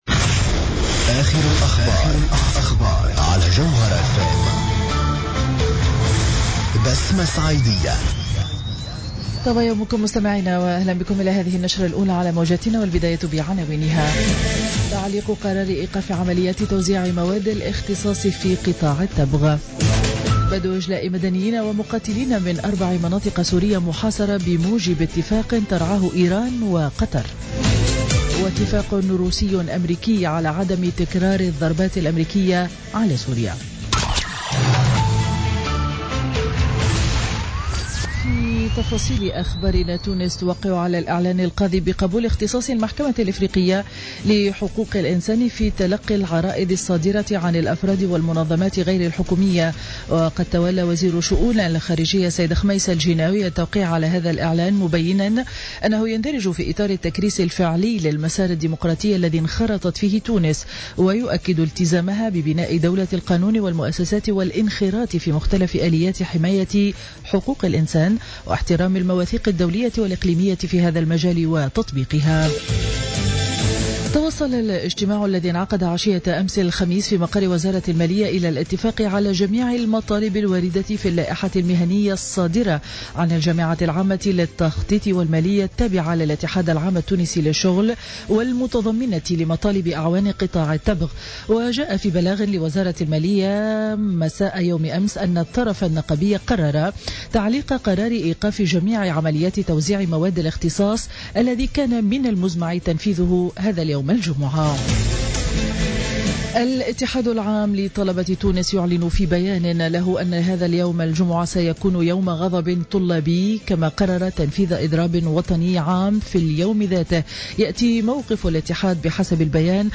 نشرة أخبار السابعة صباحا ليوم الجمعة 14 أفريل 2017